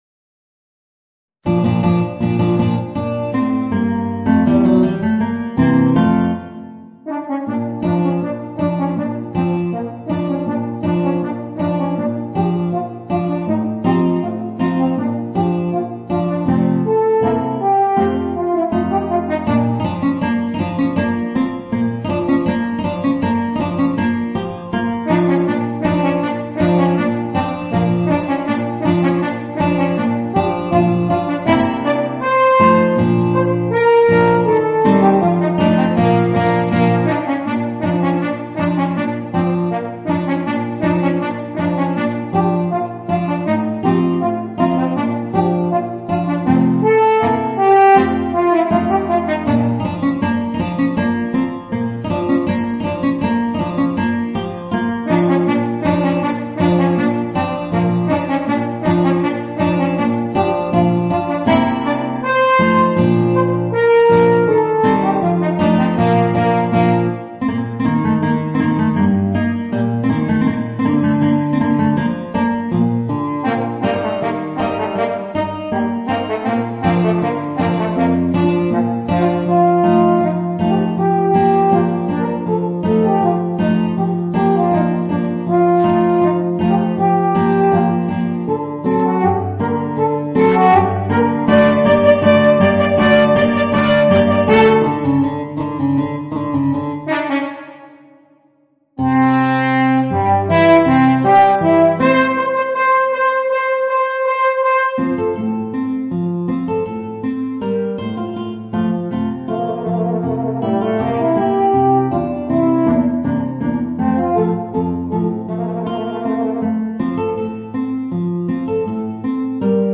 Voicing: Eb Horn and Guitar